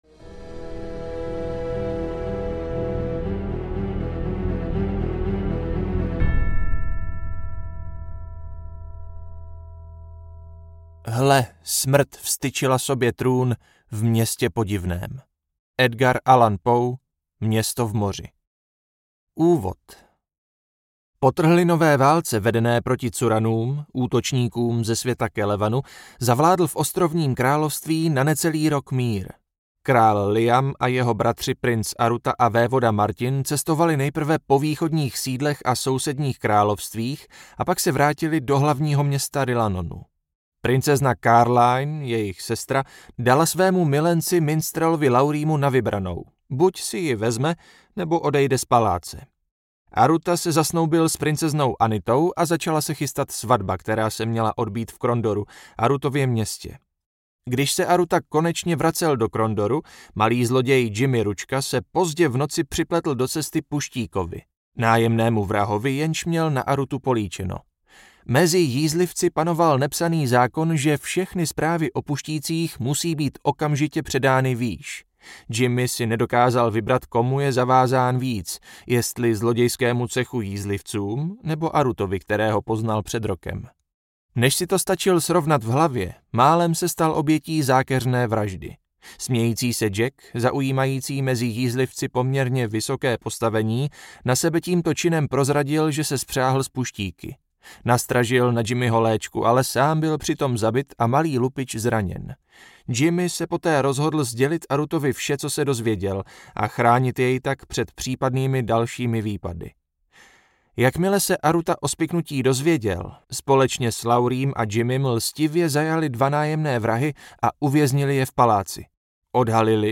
Zlo v Sethanonu audiokniha
Ukázka z knihy